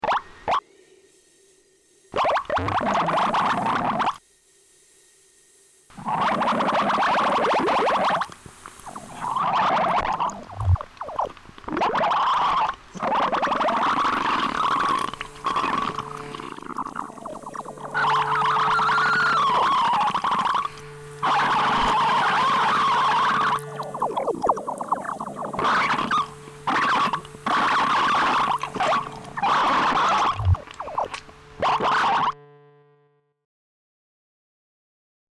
Son bulles